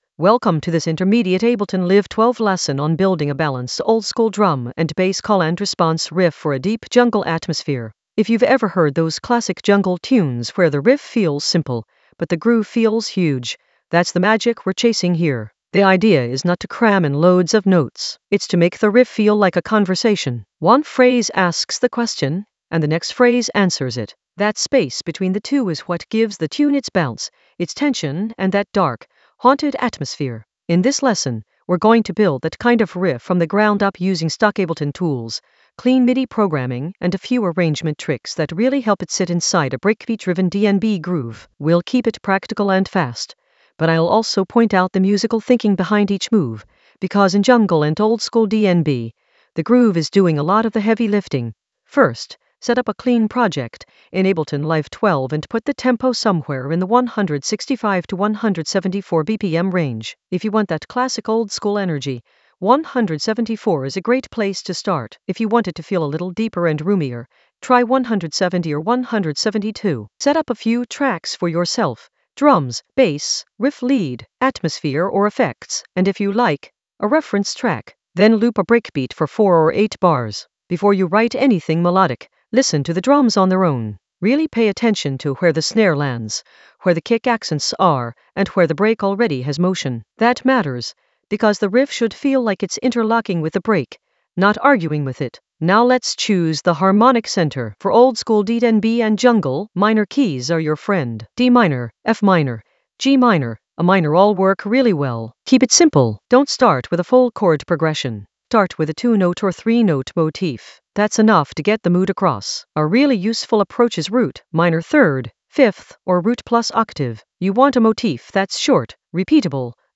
An AI-generated intermediate Ableton lesson focused on Balance oldskool DnB call-and-response riff for deep jungle atmosphere in Ableton Live 12 in the Groove area of drum and bass production.
Narrated lesson audio
The voice track includes the tutorial plus extra teacher commentary.